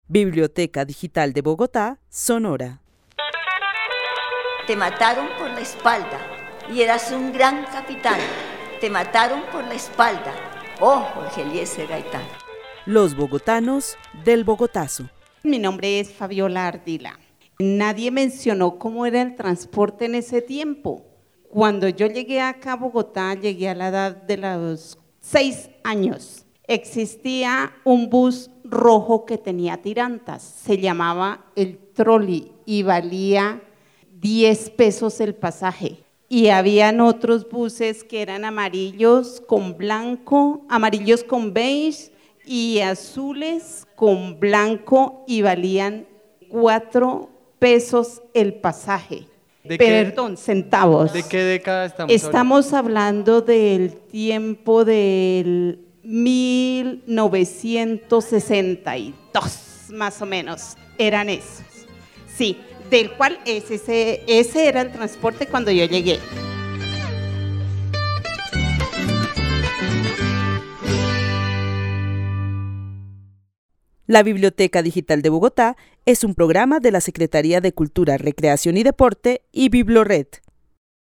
Testimonio donde se refiere al transporte público en la ciudad de Bogotá en la década de 1960, el tipo de buses que existían y el precio que tenían los pasajes.